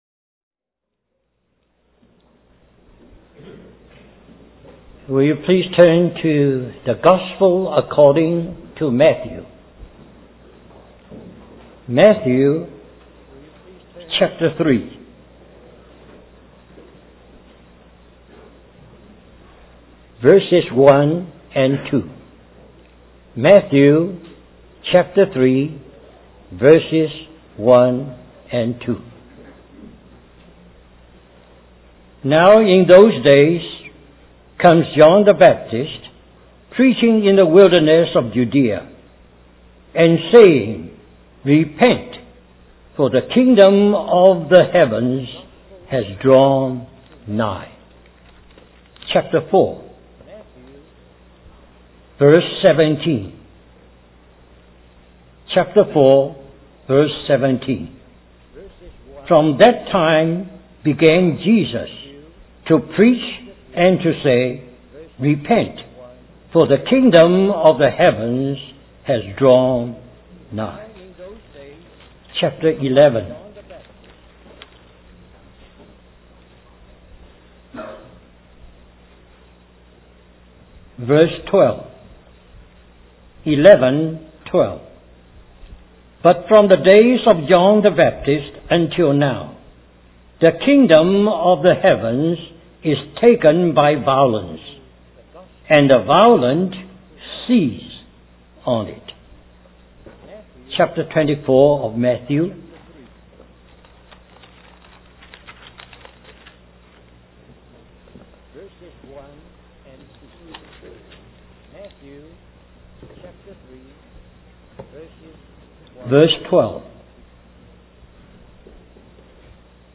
In this sermon, the preacher discusses the concept of the sudden return of Jesus Christ, comparing it to a thief coming unexpectedly.